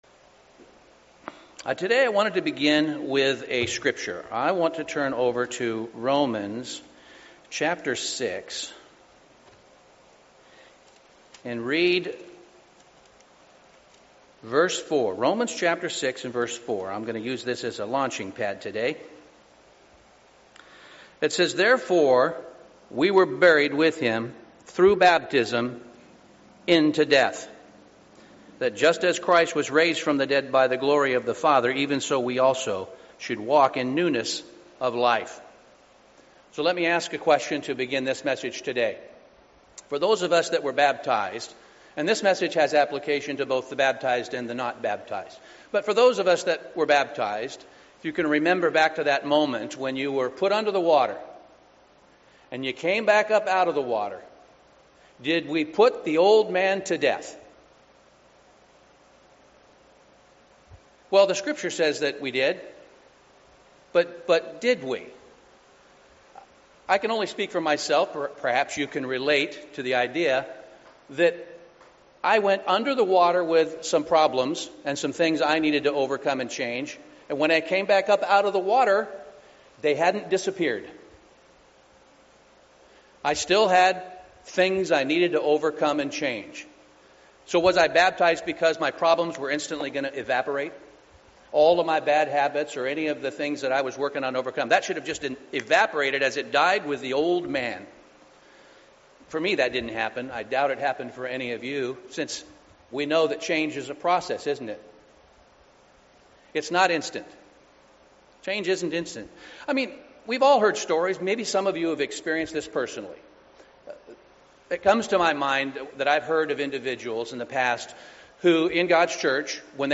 Sermons
Given in Portland, OR